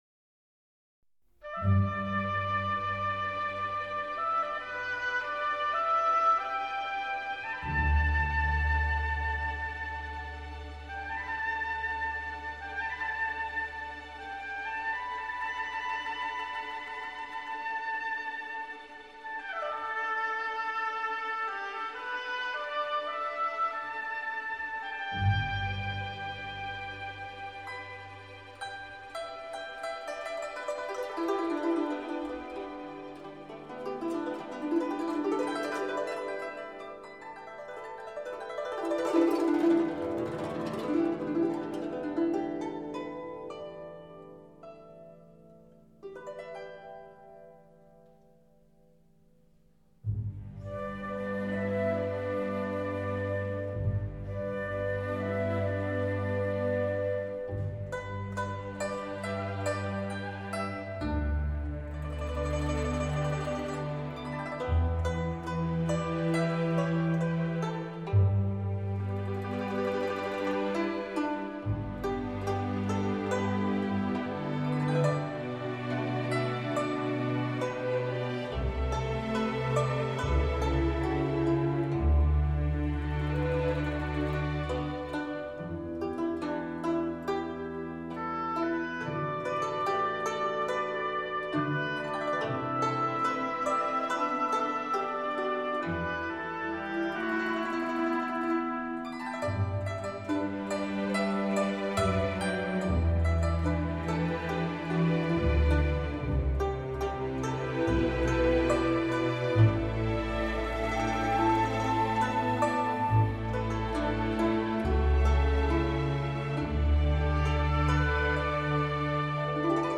古筝